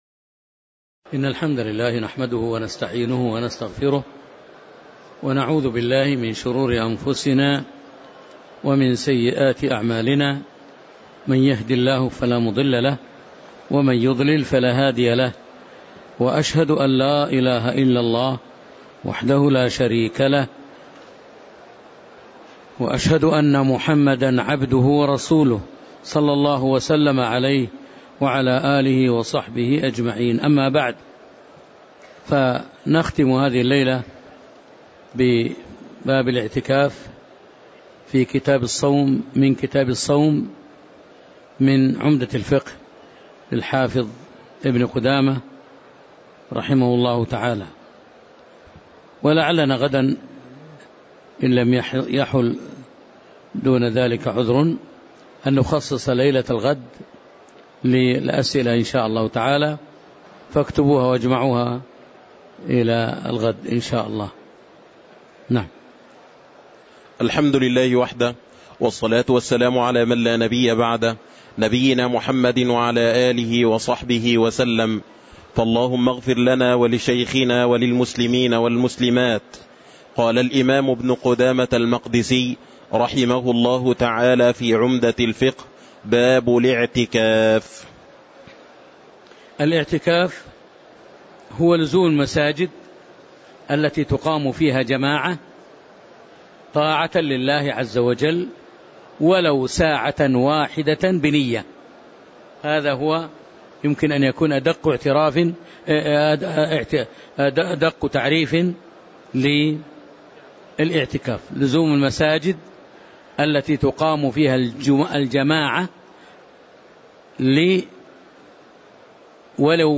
تاريخ النشر ١٧ رمضان ١٤٤٥ هـ المكان: المسجد النبوي الشيخ